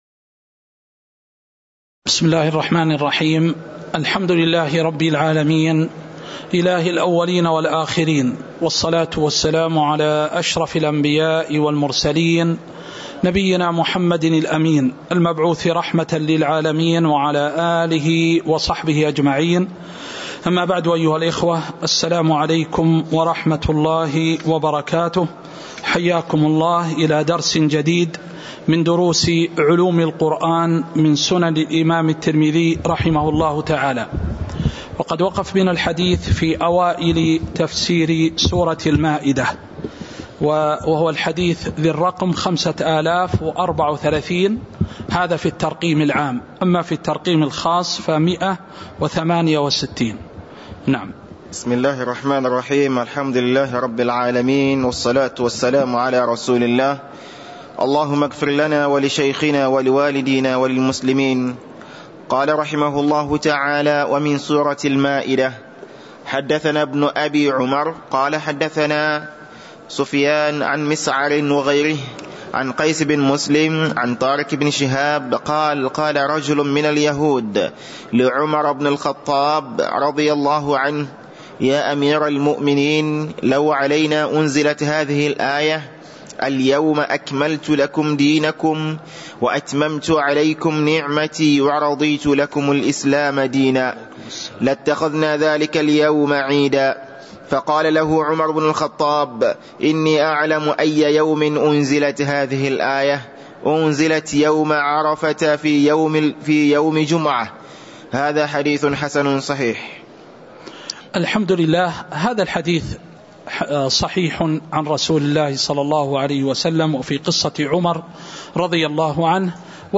تاريخ النشر ٢٥ ربيع الأول ١٤٤٣ هـ المكان: المسجد النبوي الشيخ